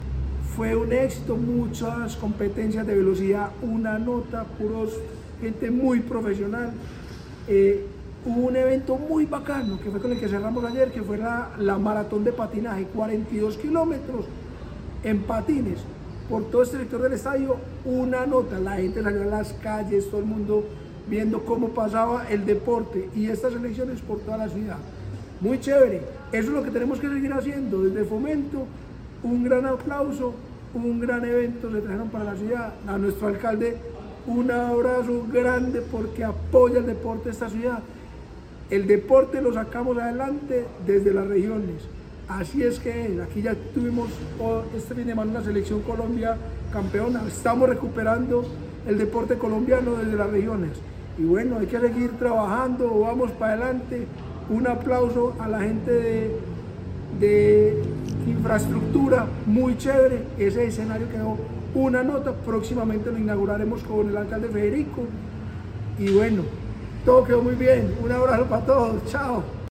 Declaraciones-del-director-del-Inder-Eduardo-Silva-Meluk.-Patinadores-Colombianos.mp3